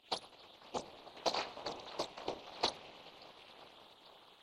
描述：吉他声的随机成分。